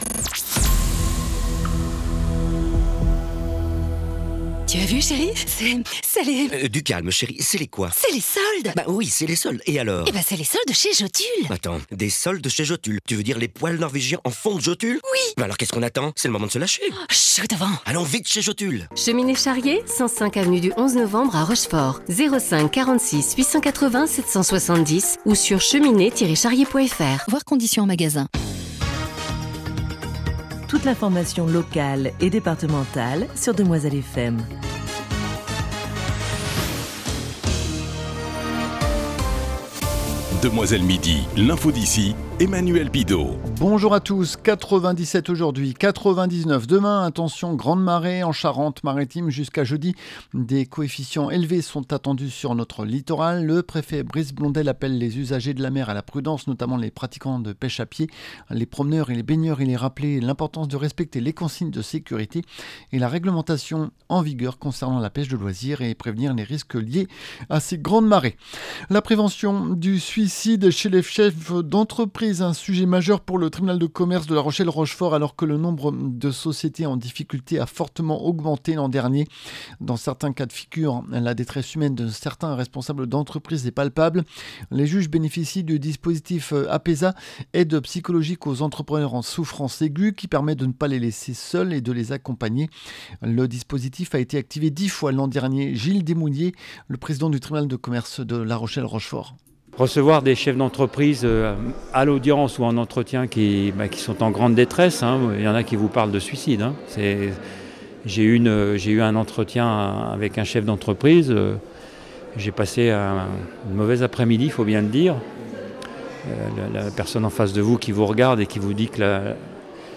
Le journal du 02 Février 2026